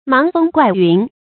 盲風怪云 注音： ㄇㄤˊ ㄈㄥ ㄍㄨㄞˋ ㄧㄨㄣˊ 讀音讀法： 意思解釋： 指急驟的風云 出處典故： 宋 姜夔《圣宋鐃歌吹曲 時雨霈》：「五領之君， 盲風怪云 ，毒蛇臻臻，相其不仁。」